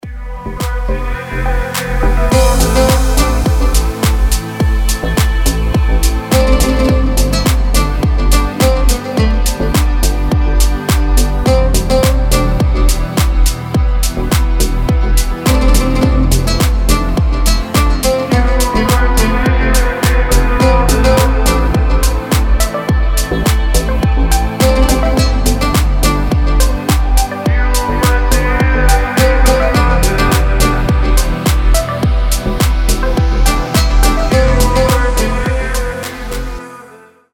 гитара
красивые
deep house
релакс
восточные
расслабляющие